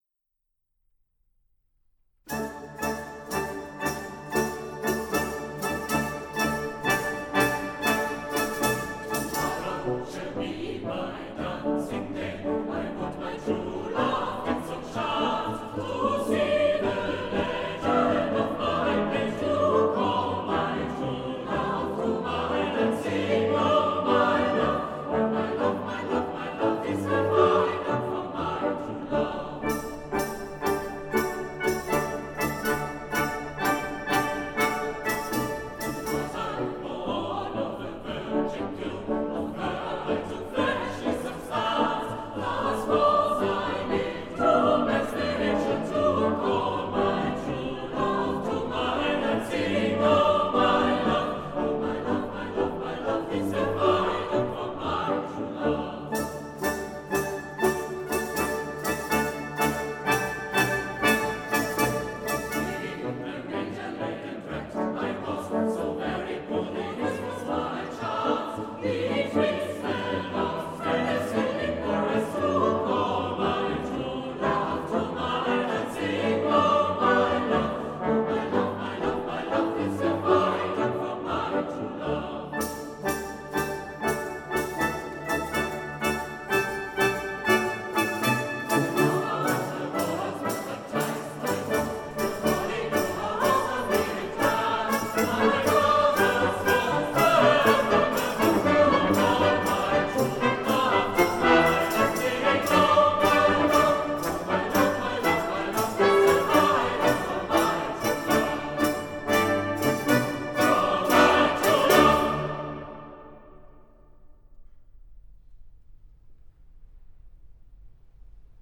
Eine (relativ alte) Aufnahme aus dem Weihnachtskonzert des Jungen Chores Aachen aus dem Jahr 2007:
der junge chor aachen
Luxemburg Brass Ensemble
07.12.2007, Klosterkirche Rolduc, Kerkrade